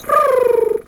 pigeon_2_emote_09.wav